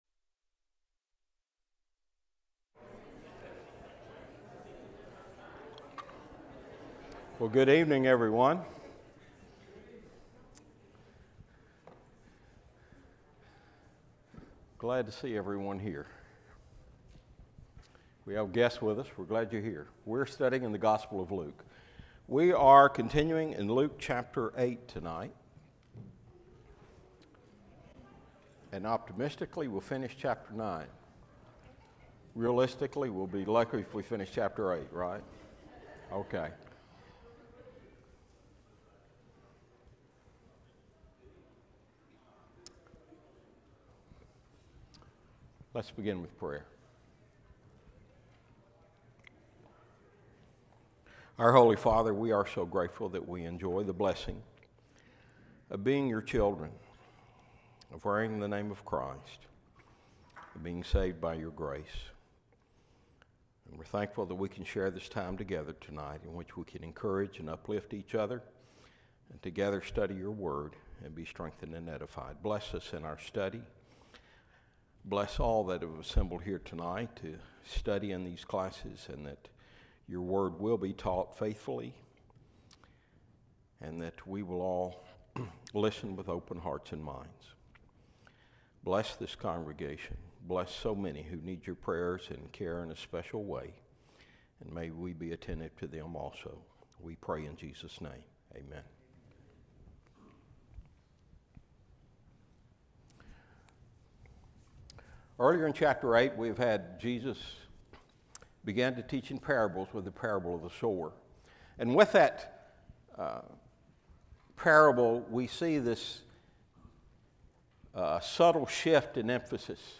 Bible_Class_-_Luke_8__9.mp3